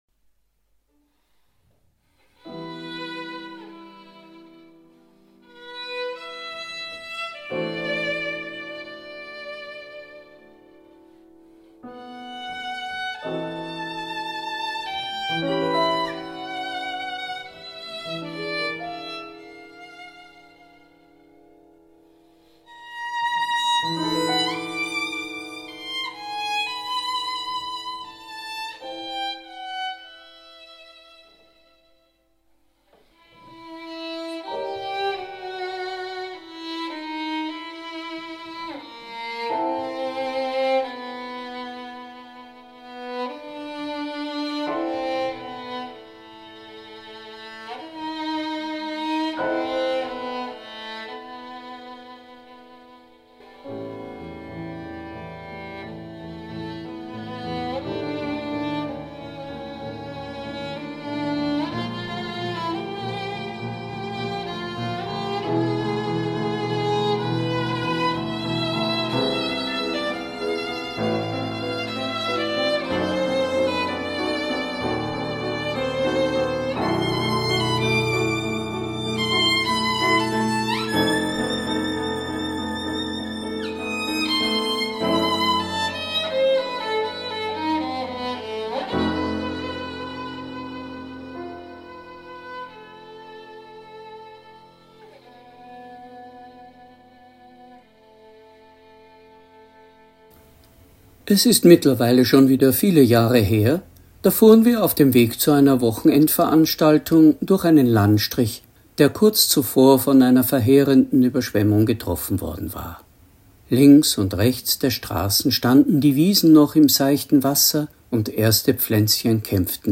Predigt | NT01 Matthäus 7,24-27 par Vom Haus auf Fels und dem Haus auf Sand